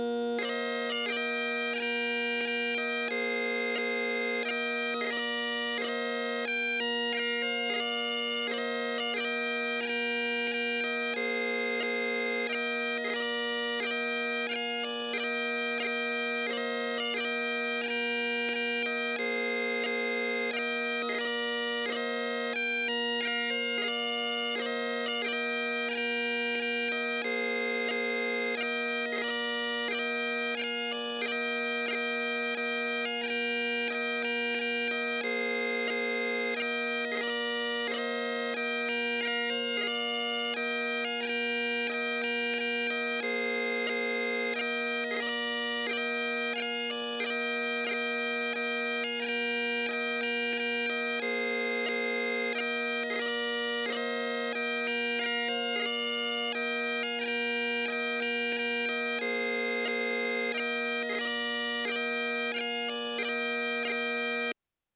I’ve attached the link for the tune and a recording of the tune (with seconds) below.